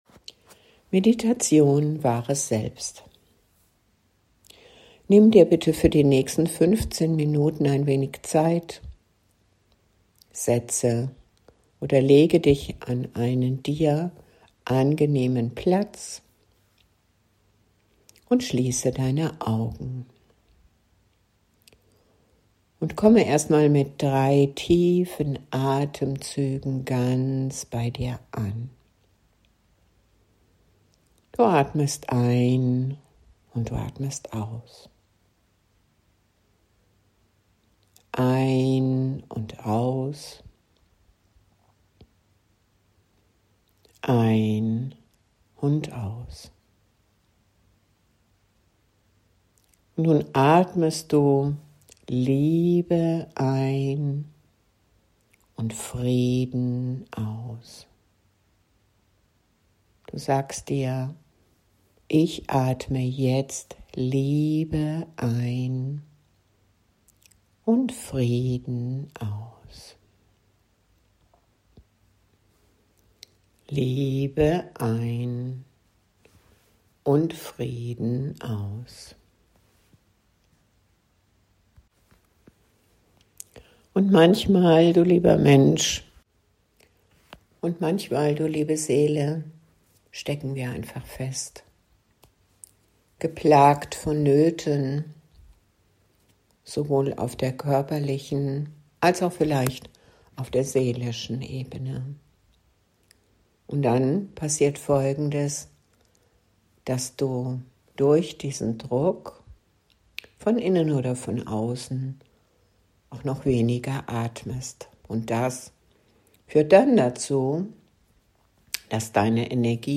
Meditation "Wahres Selbst"